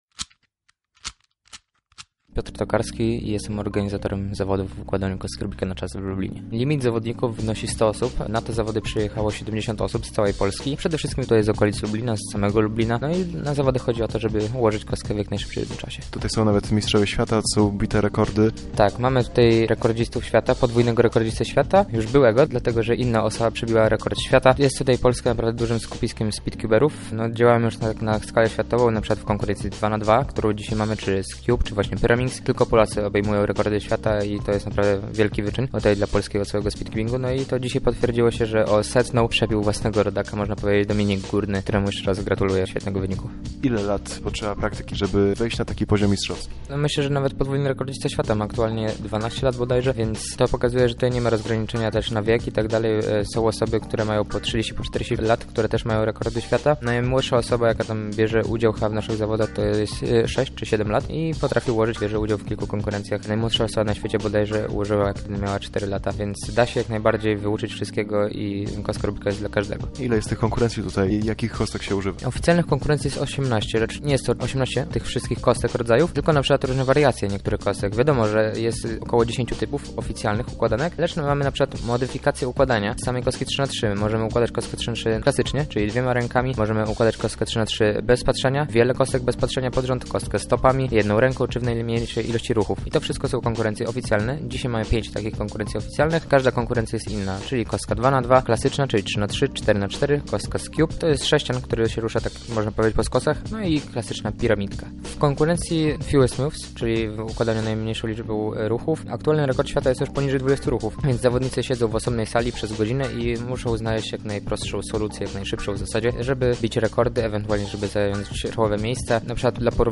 Na zawodach Lubelskiej Ligi Speedcubingu był nasz reporter